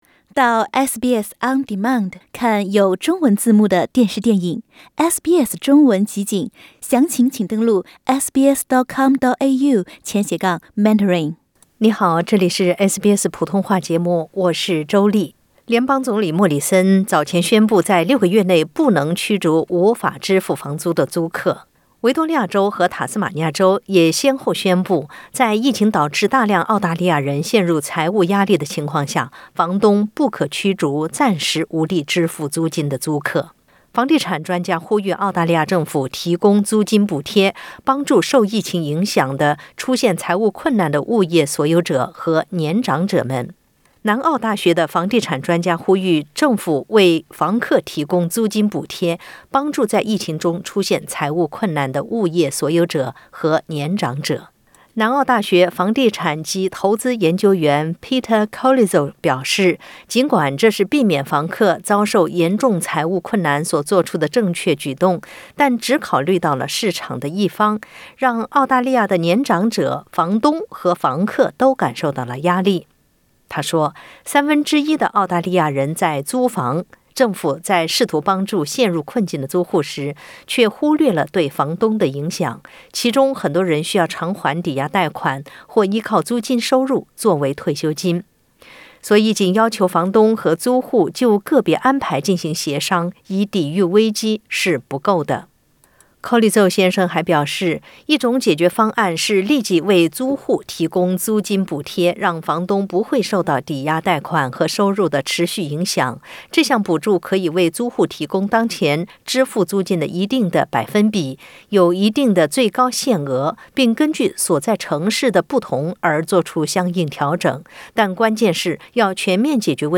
点击上方图片收听录音报道。